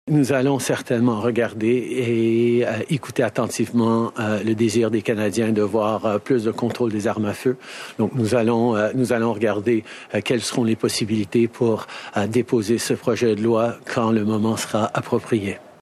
Point de presse de Justin Trudeau: la tuerie de la Nouvelle-Écosse à l’avant-plan